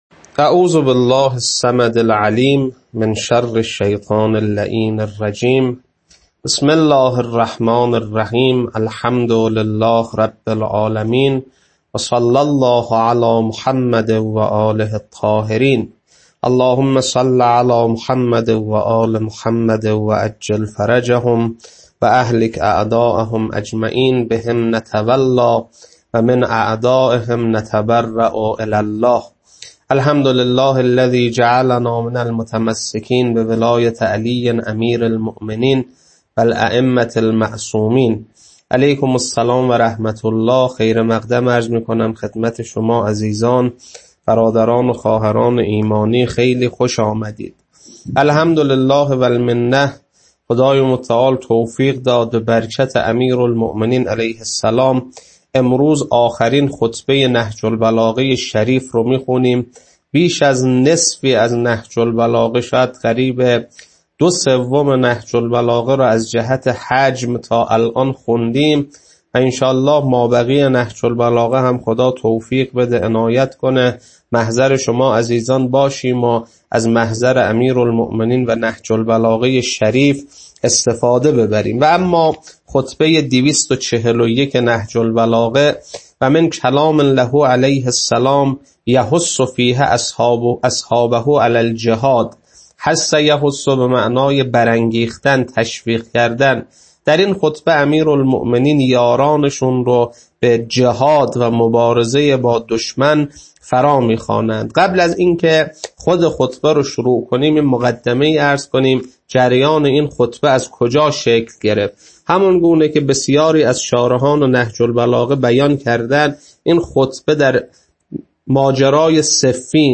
خطبه-241.mp3